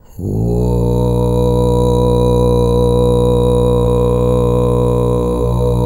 TUV5 DRONE01.wav